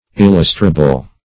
Illustrable \Il*lus"tra*ble\, a.